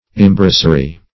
imbracery - definition of imbracery - synonyms, pronunciation, spelling from Free Dictionary Search Result for " imbracery" : The Collaborative International Dictionary of English v.0.48: Imbracery \Im*bra"cer*y\, n. Embracery.